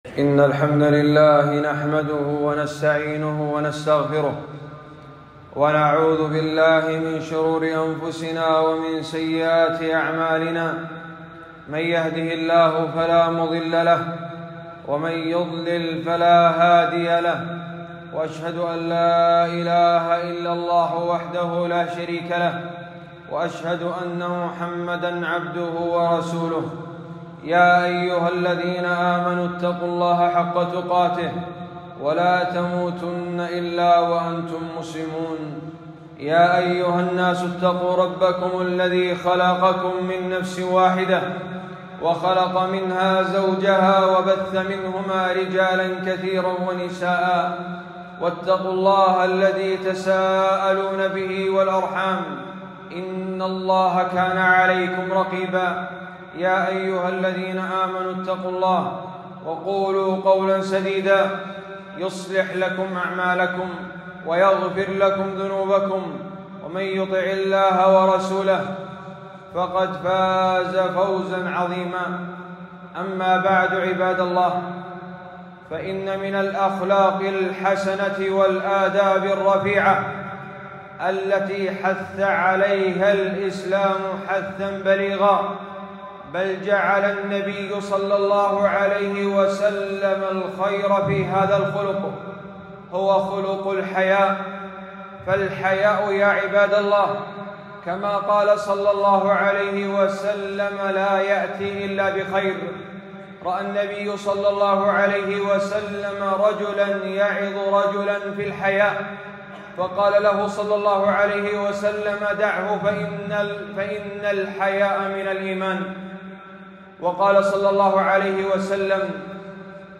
خطبة - الحياء لا يأتي إلا بخير